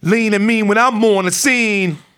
RAPHRASE12.wav